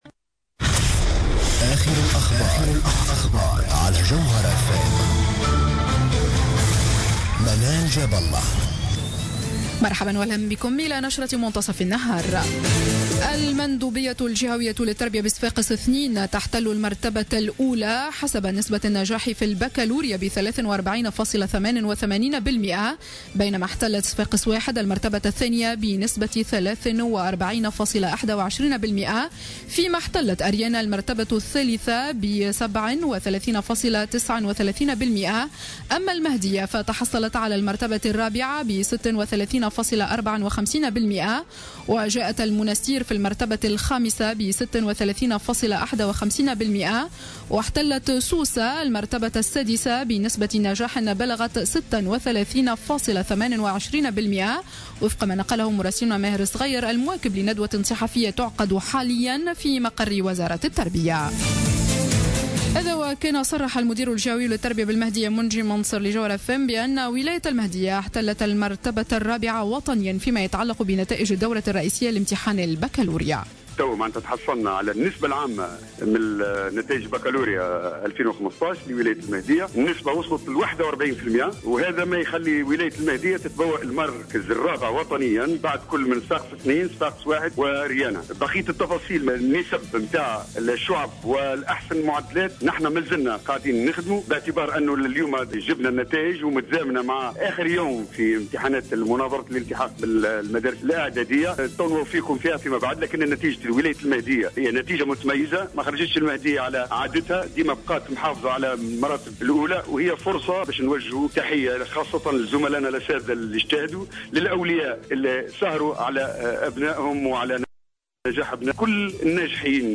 نشرة أخبار منتصف النهار ليوم السبت 20 جوان 2015